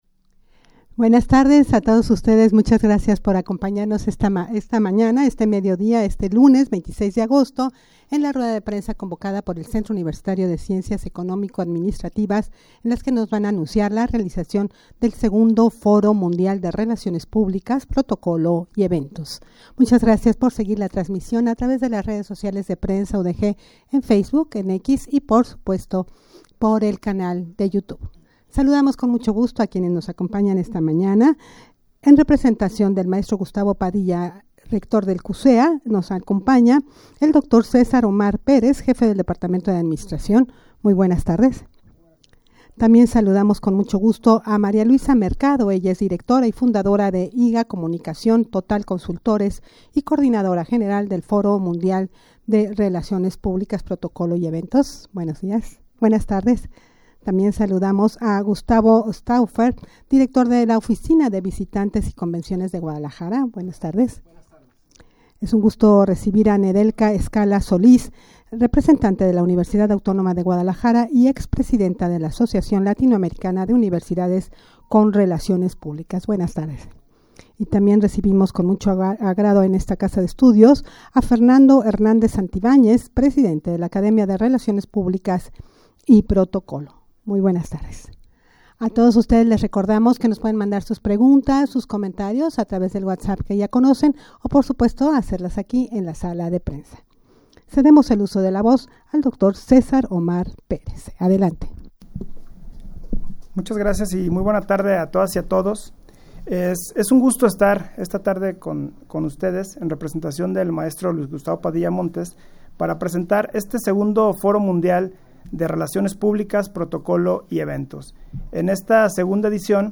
Los asistentes pasarán de la teoría a la práctica, los días 26 y 27 de septiembre Audio de la Rueda de Prensa 36.38 MB El Centro Universitario de Ciencias Económico Administrativas (CUCEA) de la UdeG invita al 2do.
rueda-de-prensa-detalles-del-segundo-foro-mundial-de-relaciones-publicas-protocolo-y-eventos.mp3